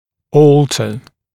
[‘ɔːltə][‘о:лтэ]менять, изменять, переделывать